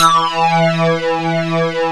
SYNC E4.wav